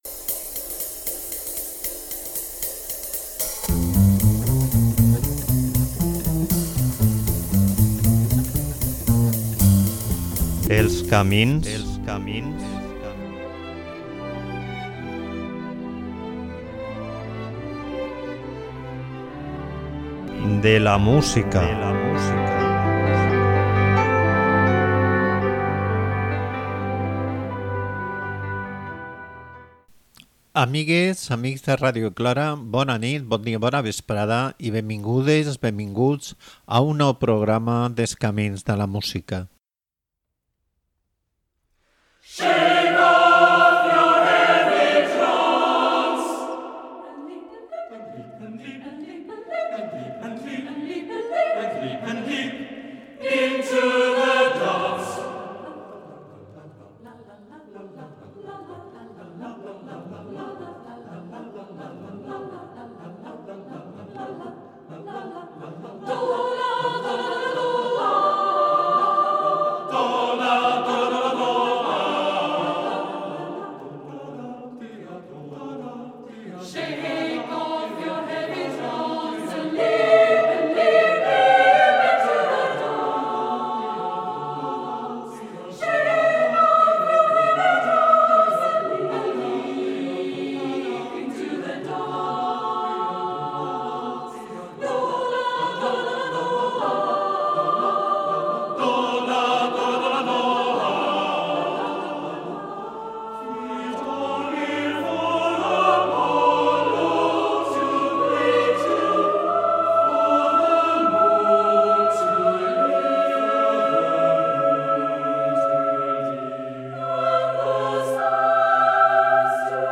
cor mixt